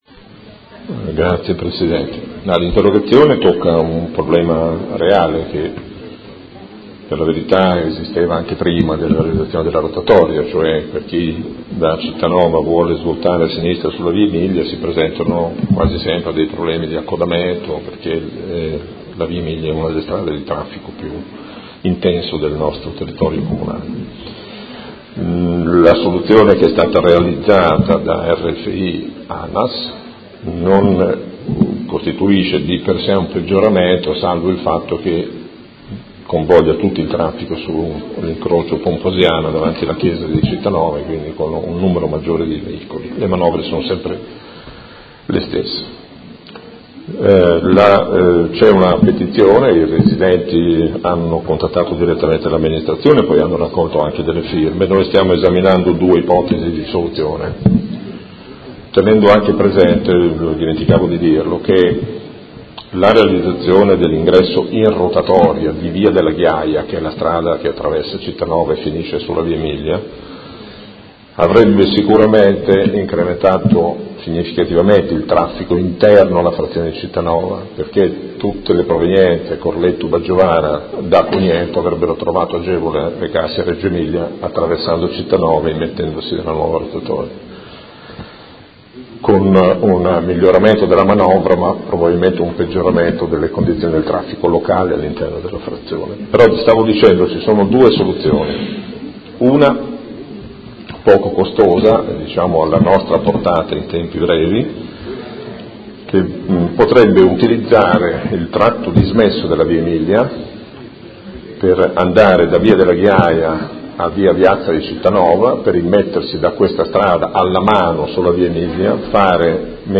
Seduta del 13/07/2017 Risponde. Interrogazione del Consigliere Morandi (FI) avente per oggetto: La nuova viabilità della Via Emilia a Cittanova con la creazione della rotatoria necessaria all’accesso per il nuovo scalo ferroviario, crea notevoli disagi ai residenti di Cittanova a sud della Via Emilia